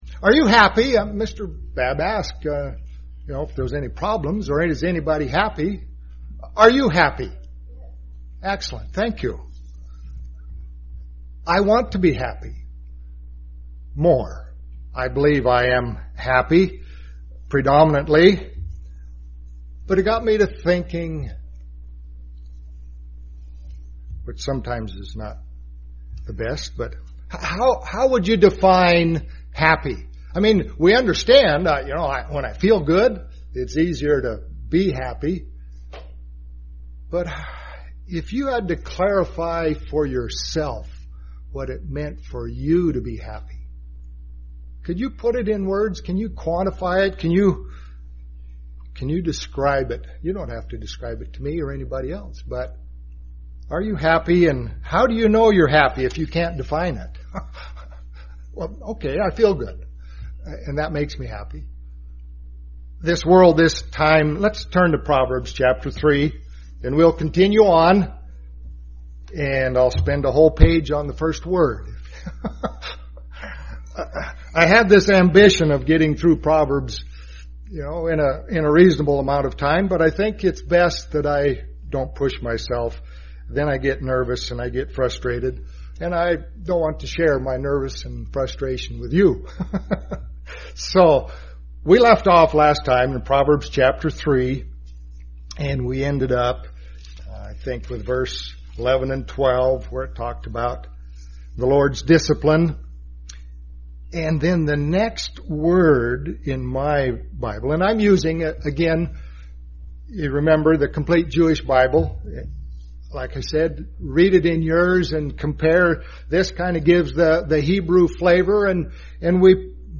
Given in Medford, OR
UCG Sermon Studying the bible?